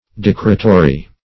Decretory \Dec"re*to*ry\, a. [L. decretorius, from decretum.